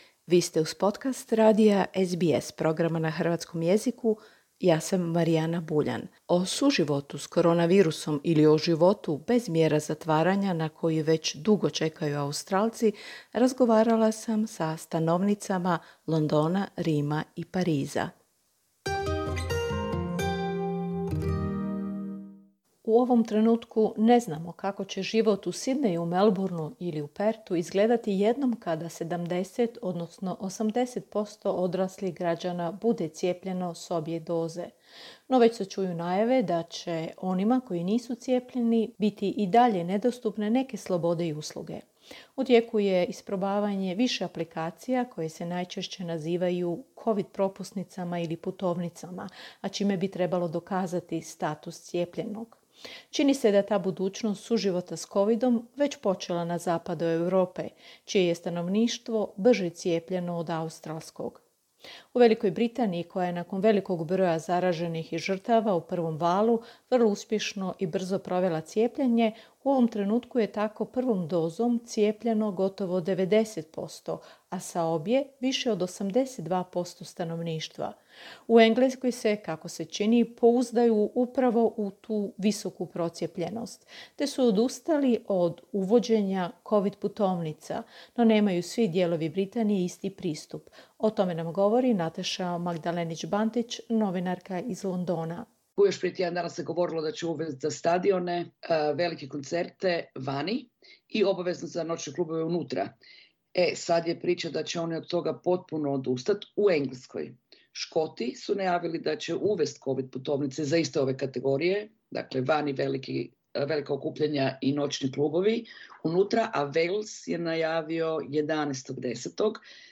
Dok je većina stanovnika Australije i dalje pod mjerama zatvaranja i čeka na povratak sloboda, u zemljama zapadne Europe već se nekoliko mjeseci "živi s virusom". O tome nam govore stanovnice Londona, Rima i Pariza.